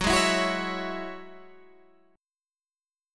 Gb7sus2 chord